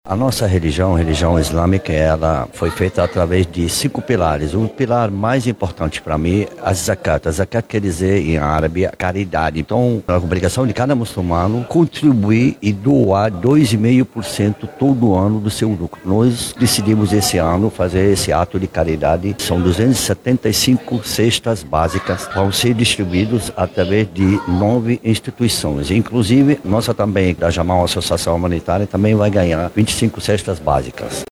SONORA-1-DOACAO-CESTAS-MULCUMANOS-.mp3